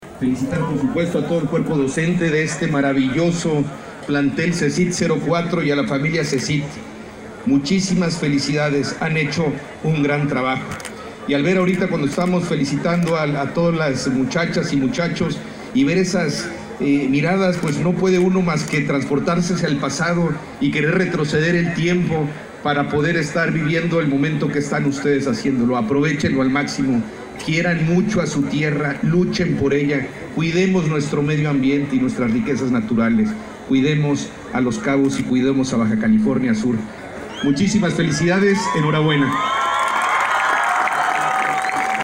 En representación del gobernador Carlos Mendoza Davis, el titular de la Secretaría de Planeación Urbana, Infraestructura y Movilidad (SEPUIM), asistió a la ceremonia de graduación de alumnos del Colegio de Estudios Científicos y Tecnológicos (CECYT) #04 de San José del Cabo, donde se graduaron más de 500 jóvenes que continuarán con su educación profesional.